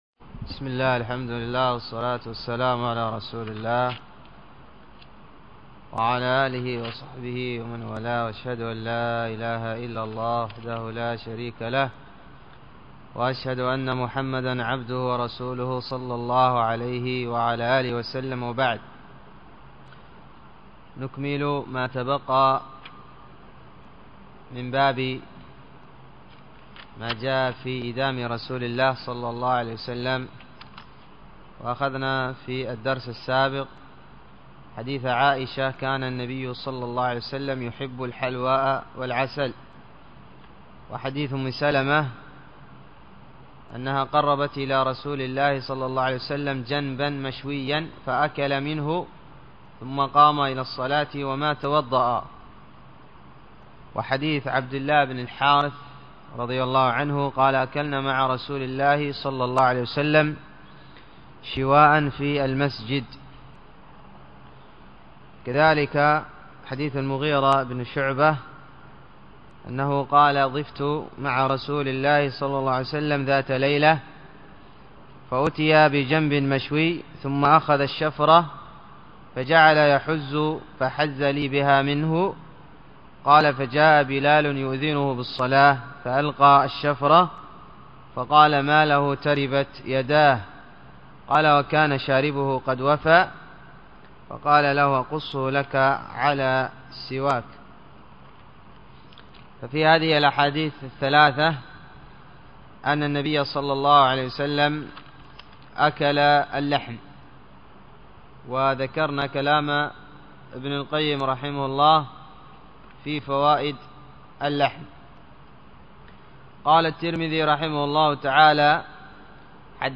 الدرس الخامس و الثلاثون من دروس الشمائل المحمدية